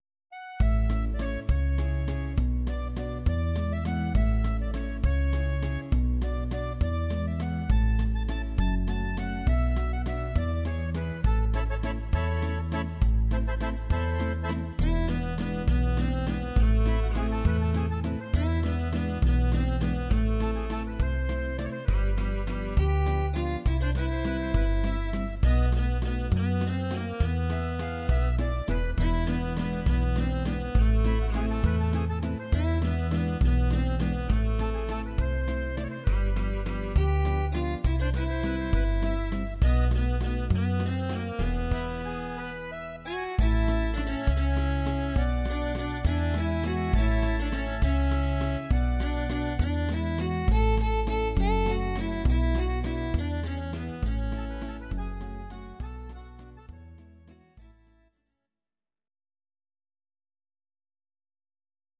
Audio Recordings based on Midi-files
Our Suggestions, Pop, Ital/French/Span, 1990s